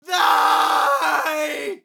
Horror Scream Sample
Categories: Vocals Tags: dry, english, fill, Horror, male, sample, Scream, Tension
TEN-vocal-fills-100BPM-A-1.wav